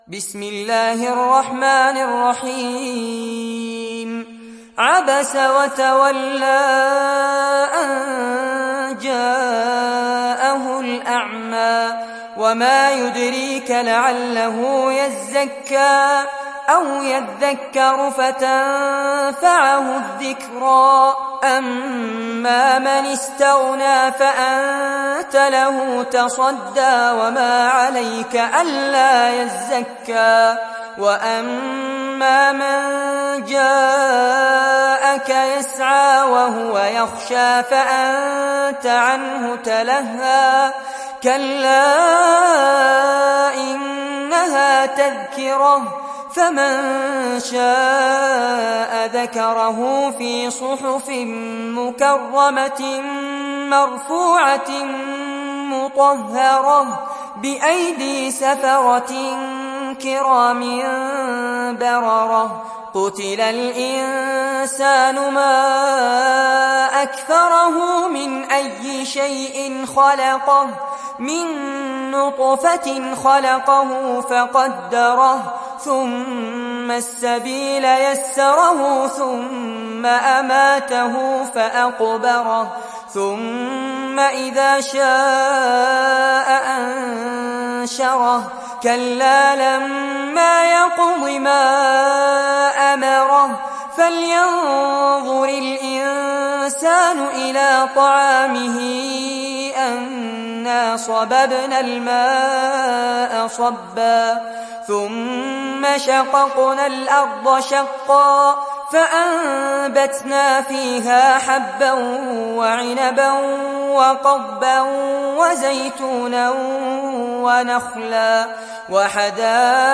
دانلود سوره عبس mp3 فارس عباد روایت حفص از عاصم, قرآن را دانلود کنید و گوش کن mp3 ، لینک مستقیم کامل